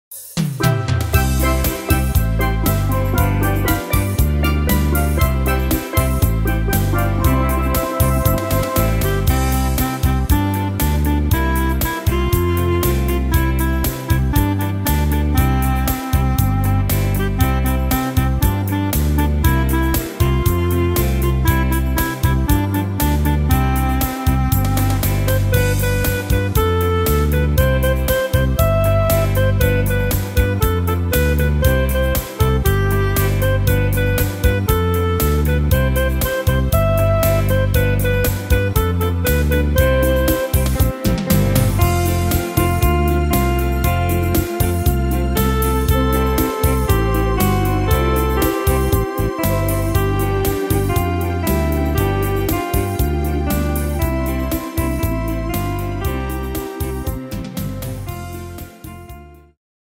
Tempo: 118 / Tonart: C-Dur